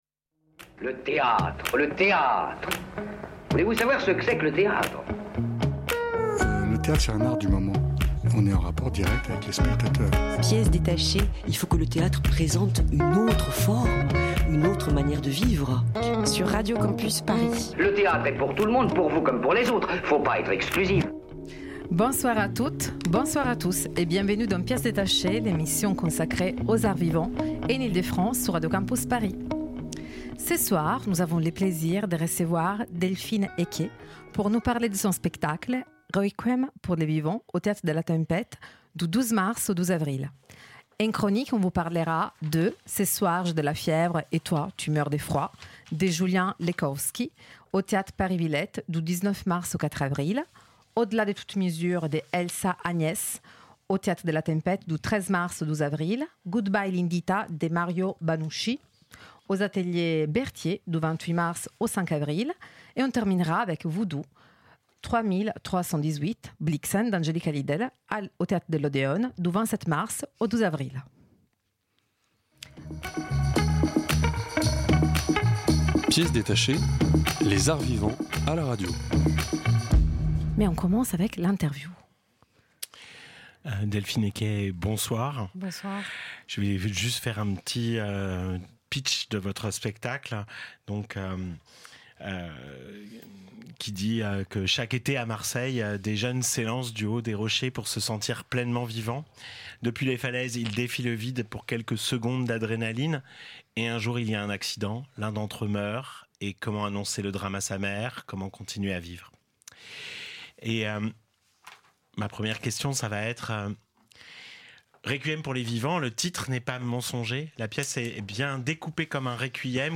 La mort ça intEntretien :